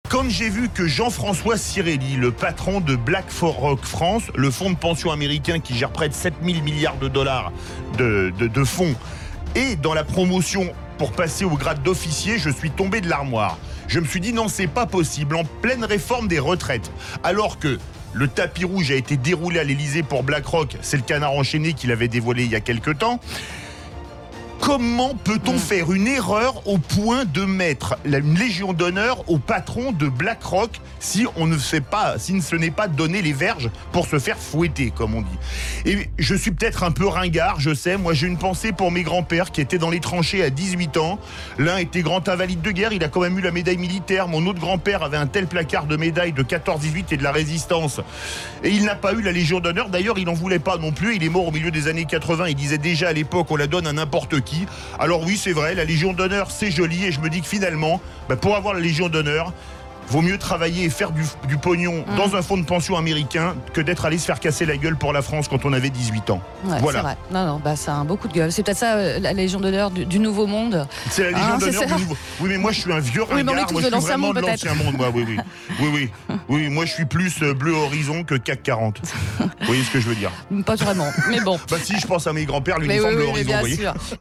a poussé un coup de gueule à l’antenne suite à la promotion au sein de l’Ordre national de la Légion d’honneur de Jean-François Cirelli